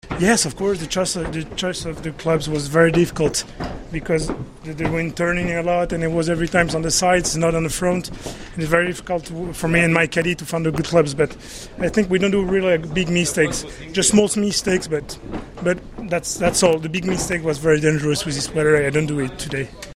O-Ton
Albert Park Circuit, Melbourne. Australian Grand Prix. BMW Sauber F1 Team driver Robert Kubica (POL) about the the race - part 2.